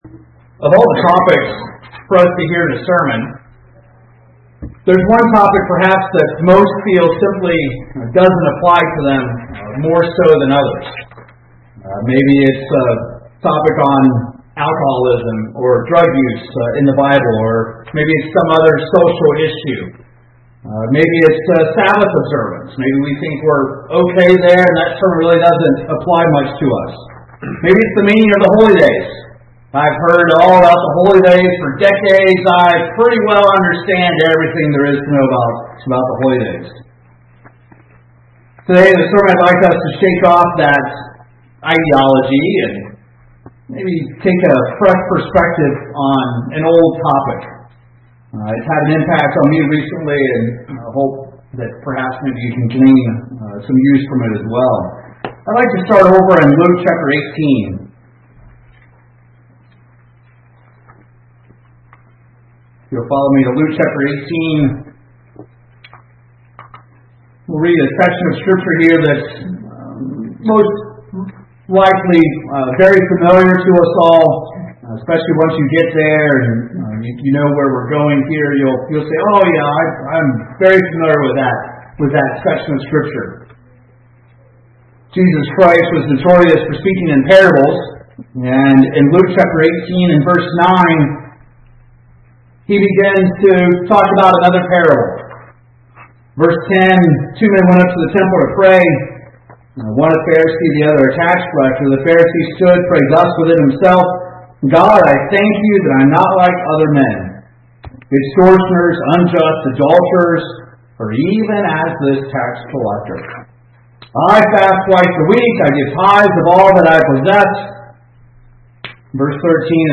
You might say a sermon on pride doesn't apply to me.